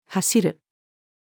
走る-female.mp3